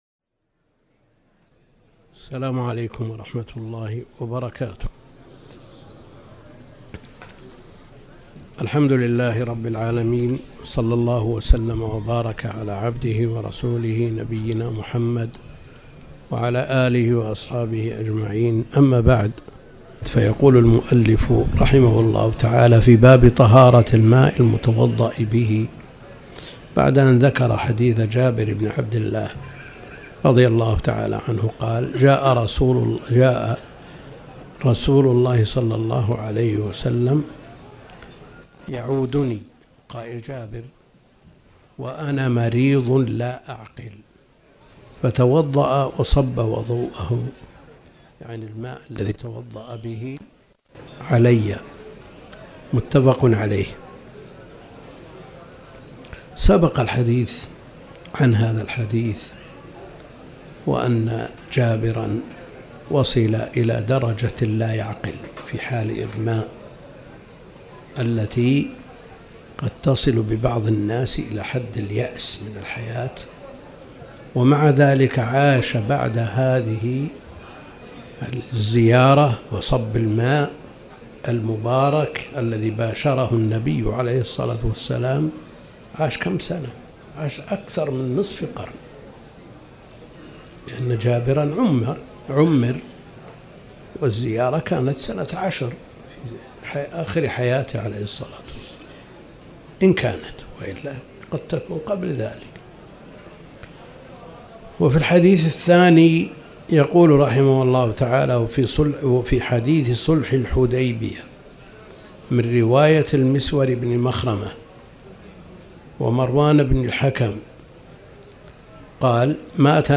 تاريخ النشر ٣ ذو القعدة ١٤٤٠ هـ المكان: المسجد الحرام الشيخ: فضيلة الشيخ د. عبد الكريم بن عبد الله الخضير فضيلة الشيخ د. عبد الكريم بن عبد الله الخضير الماء المتطهر به